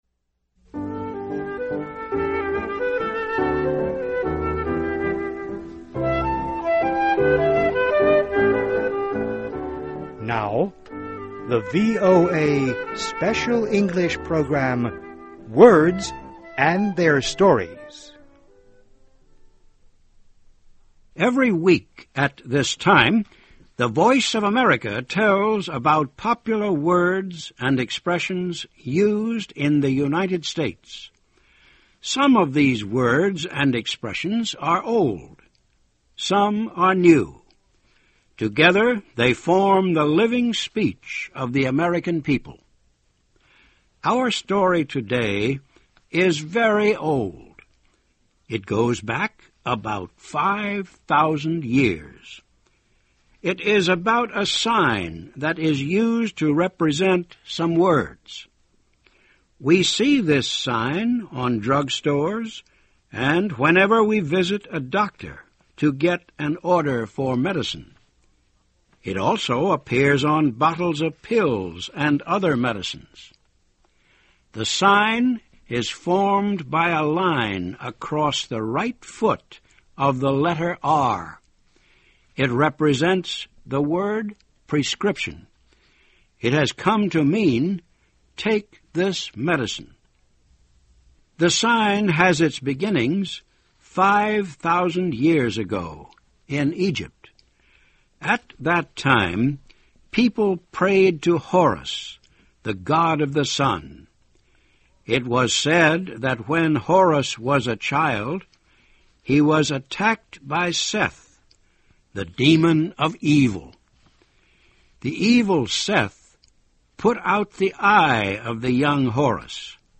Words and Their Stories: The Story of the Sign 'Rx' Seen on Medicine Bottles (VOA Special English 2009-03-21)
VOA Editor's Note: This program was first broadcast many years ago.